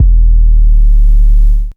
Biiiig 808.wav